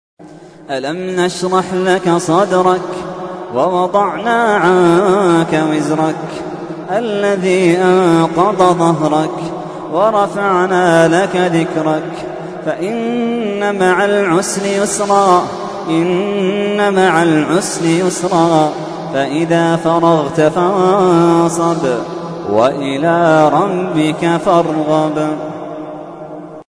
تحميل : 94. سورة الشرح / القارئ محمد اللحيدان / القرآن الكريم / موقع يا حسين